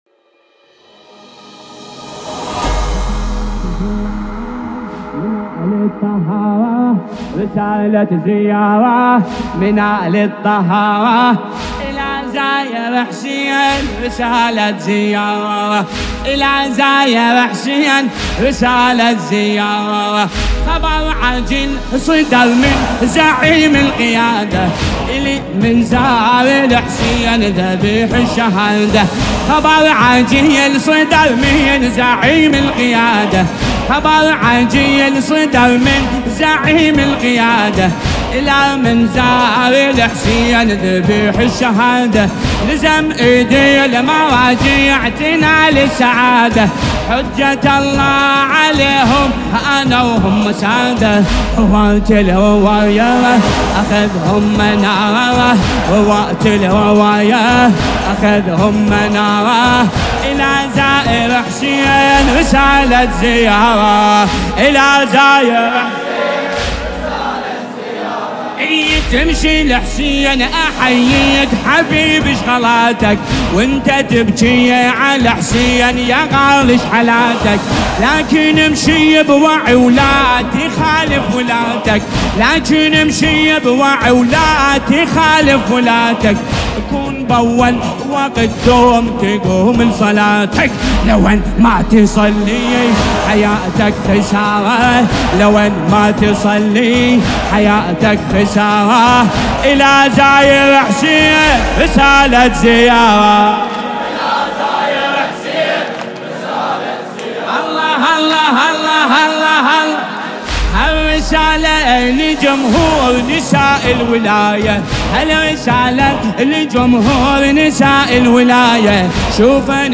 نماهنگ دلنشین عربی